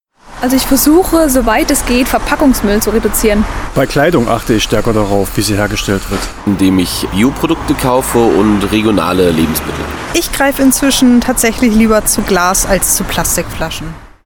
Wir haben uns auf der Straße umgehört, wo Sie auf Nachhaltigkeit achten.